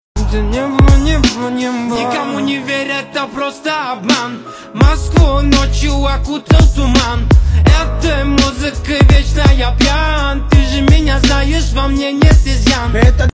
• Качество: 321 kbps, Stereo